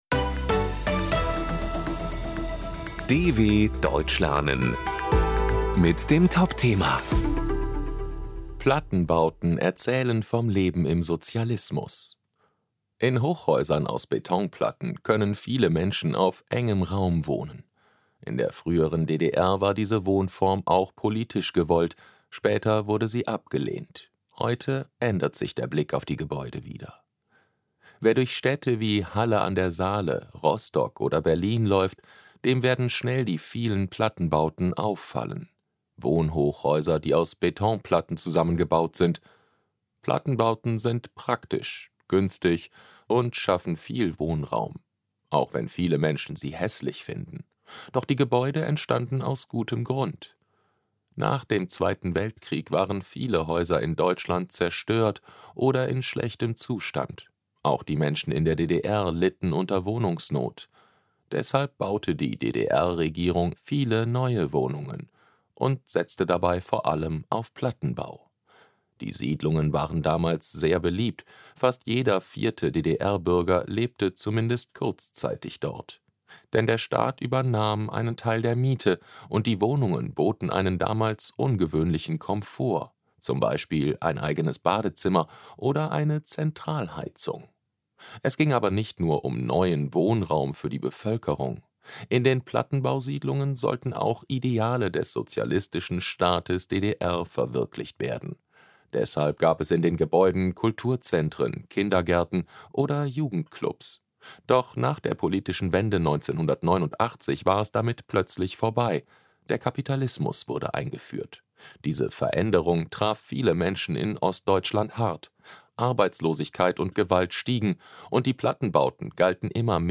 B1 | Deutsch für Fortgeschrittene: Deutsch lernen mit Realitätsbezug: aktuelle Berichte der Deutschen Welle – leicht verständlich und mit Vokabelglossar.